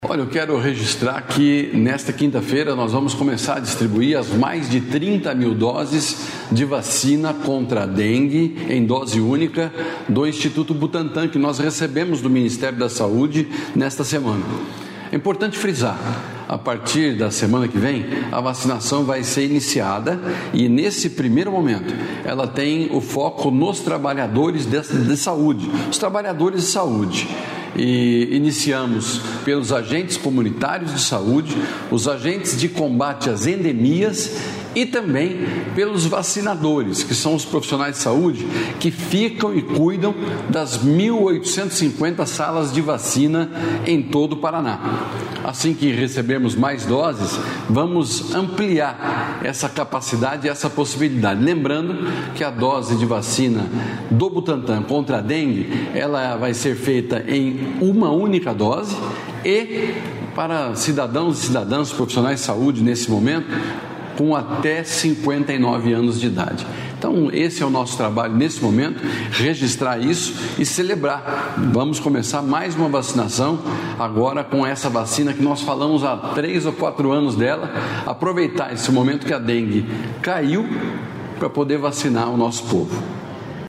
Sonora do secretário Estadual da Saúde, Beto Preto, sobre as doses da vacina do Instituto Butantan contra a dengue distribuídas para todo o Estado | Governo do Estado do Paraná